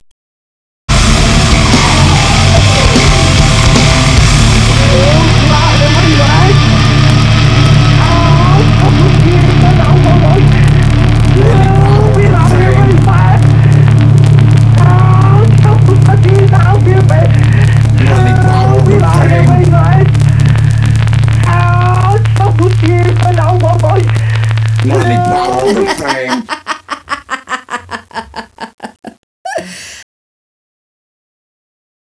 played it backwards (reverse)